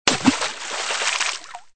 31_splashSound.mp3